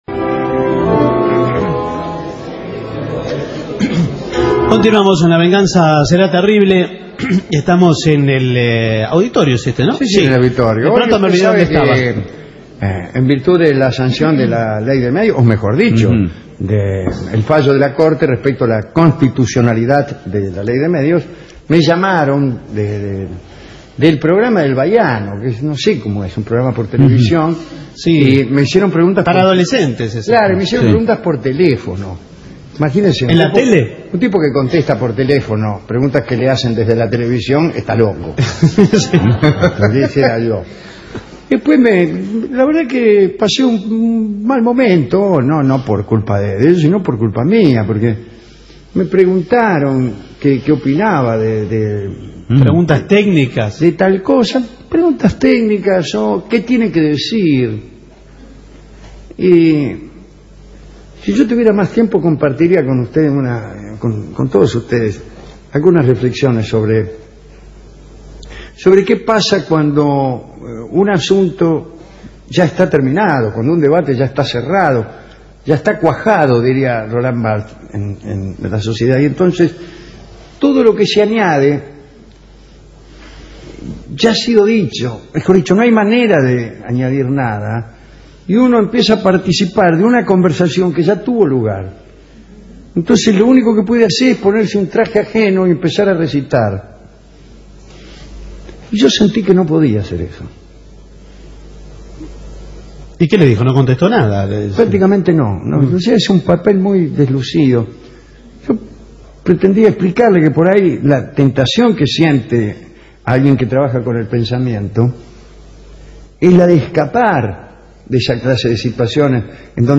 Discursos de ocasión sobre asuntos cerrados: participación deslucida de Dolina en entrevista sobre la constitucionalidad de la Ley de Medios